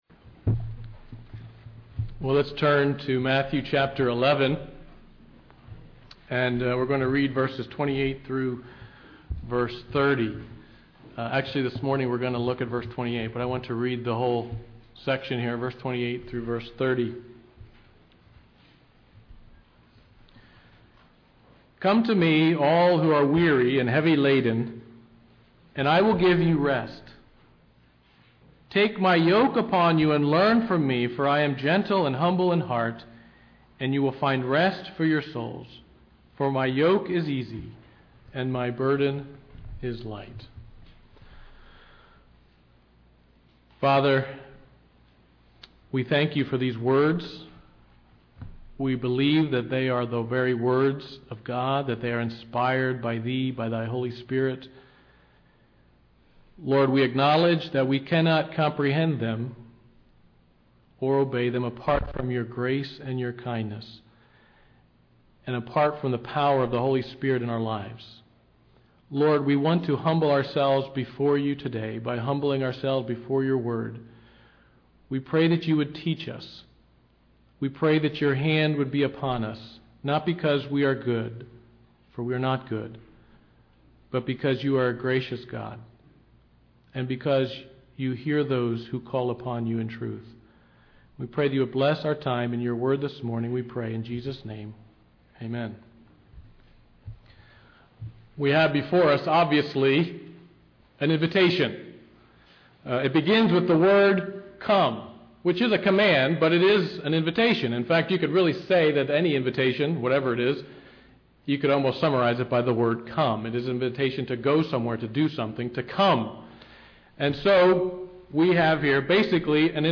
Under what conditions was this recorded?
Sunday Morning Worship Topics